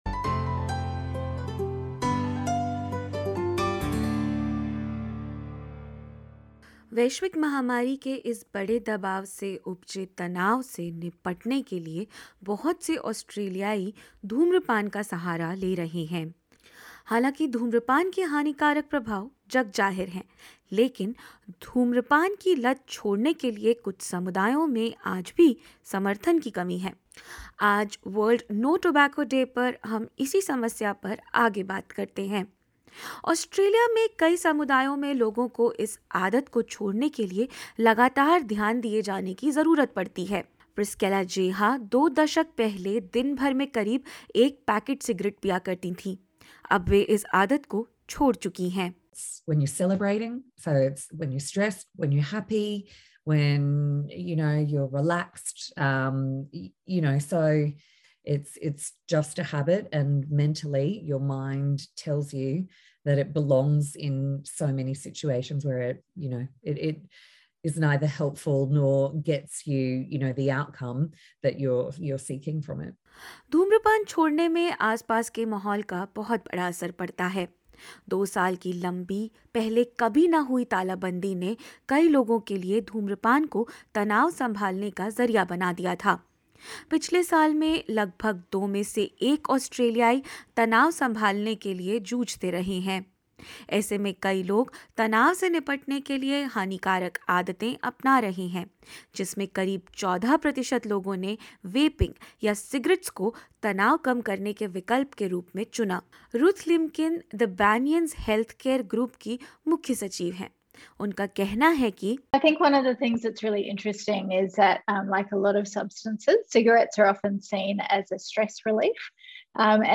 कितनी विकट है ऑस्ट्रेलिया में यह समस्या और कैसे छोड़ें सिगरेट की लत, जानिए इस रिपोर्ट में।